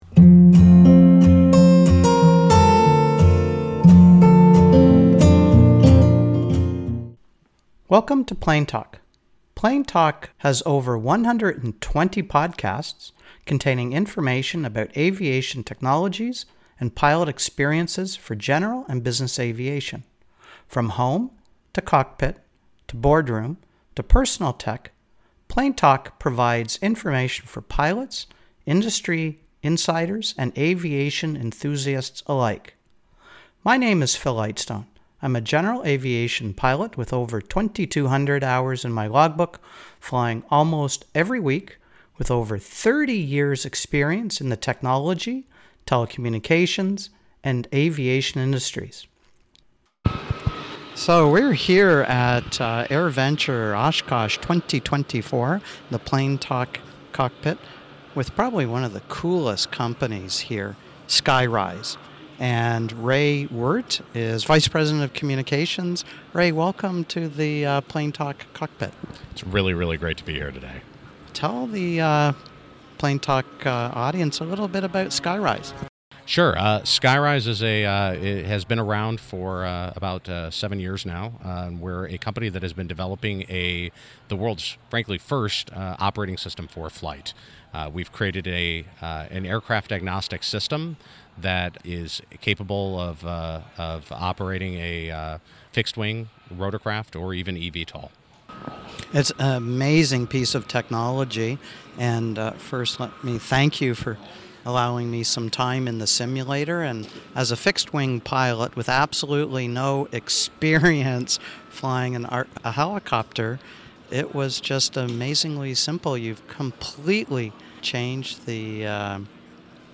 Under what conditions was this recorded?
Recorded in Skyryse’s pavilion at AirVenture 2024